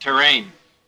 terrain.wav